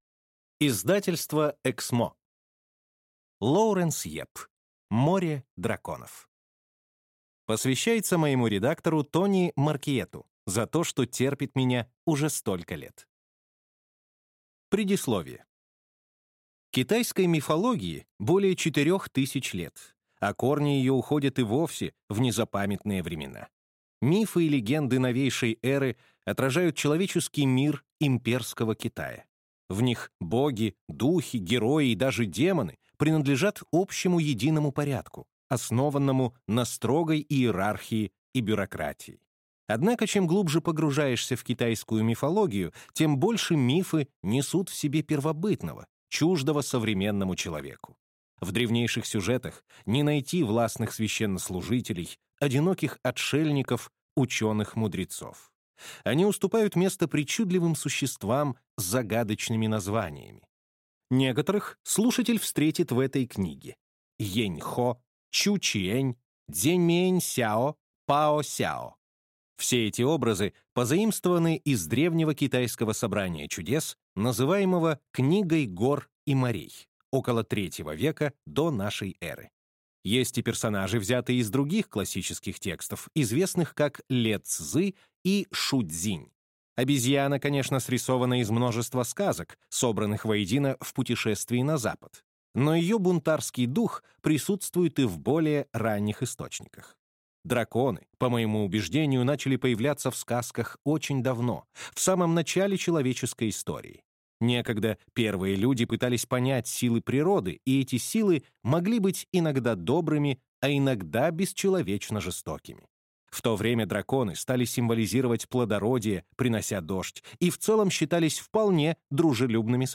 Аудиокнига Море драконов | Библиотека аудиокниг
Прослушать и бесплатно скачать фрагмент аудиокниги